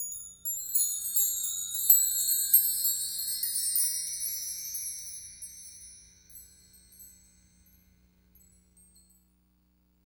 Index of /90_sSampleCDs/Roland L-CD701/PRC_Asian 2/PRC_Windchimes